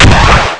SHTGUN9C.WAV